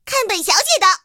T-60夜战攻击语音.OGG